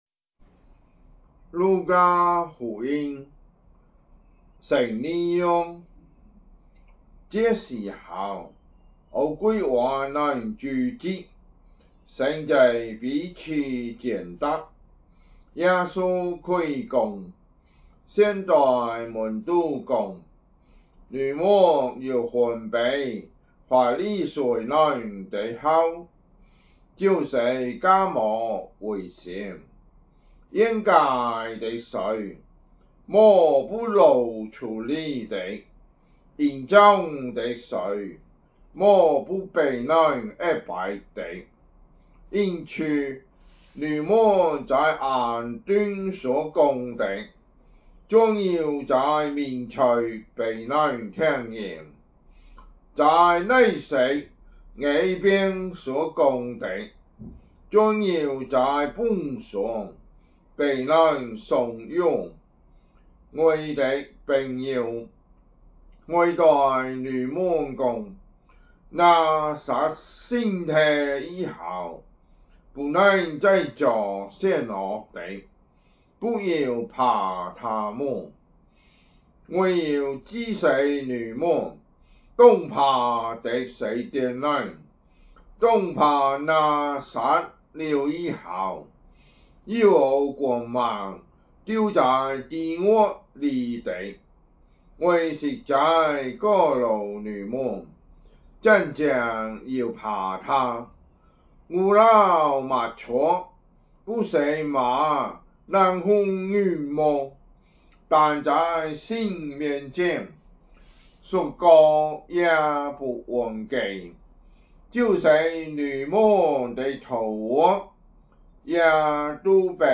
福州話有聲聖經 路加福音 12章